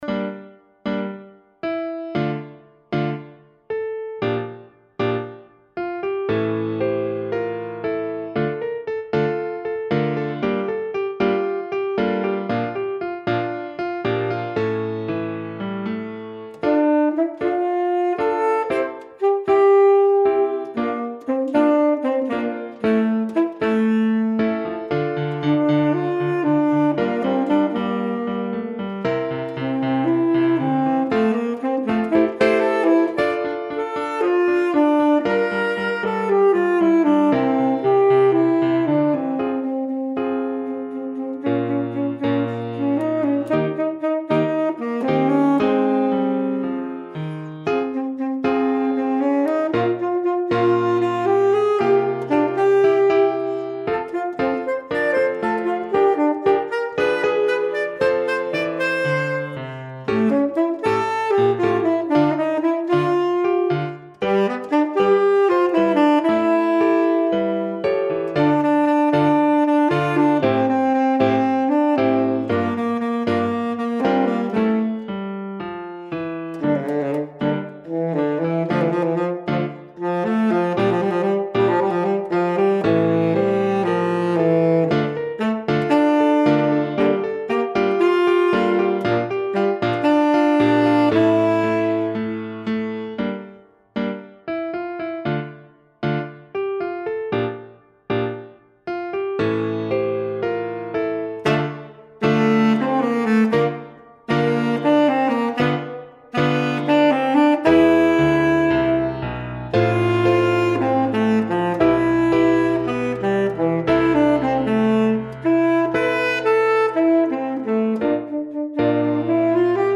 Pour saxophone alto et piano
Ecouter ici 2 extraits (saxo ténor) :